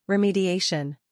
ri · mee · dee · ay · shn